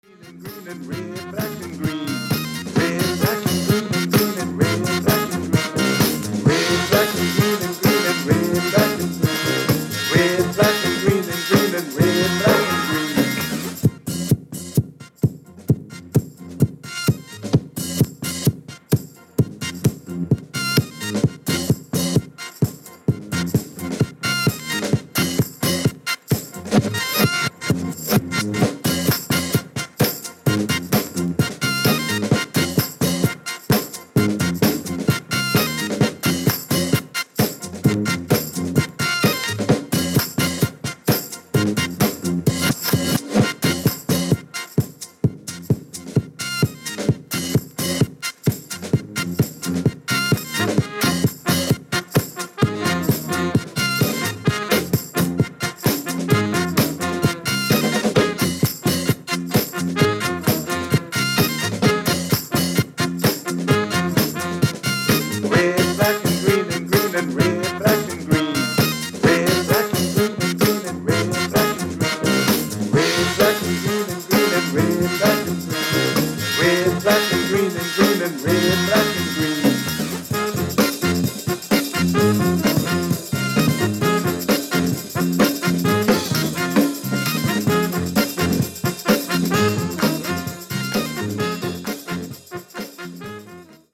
トランぺッター